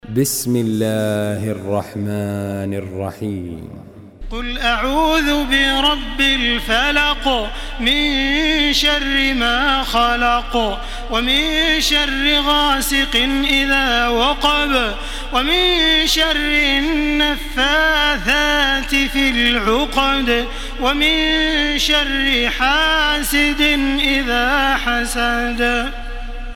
Makkah Taraweeh 1434
Murattal